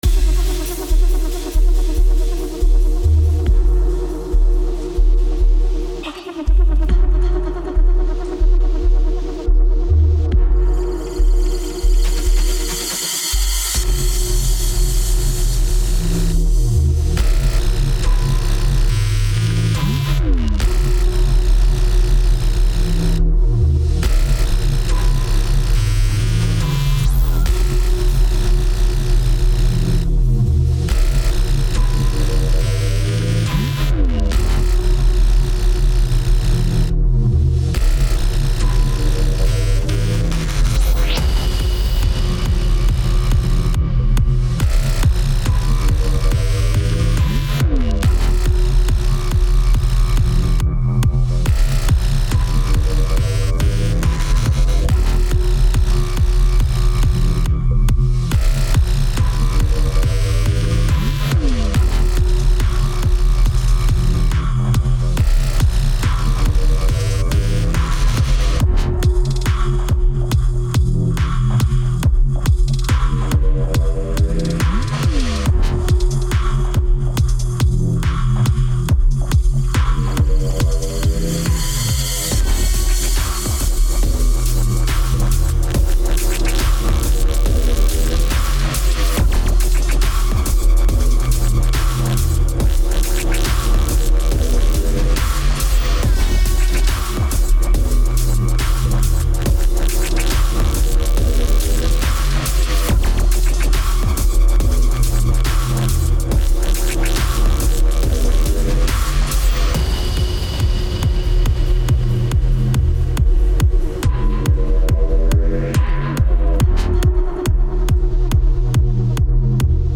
• Version Instrumental
Tempo 140BPM (Allegro)
Genre Deep Dark Dubstep
Type Instrumental
Mood Conflicting [Aggressive/energetic/sorrow]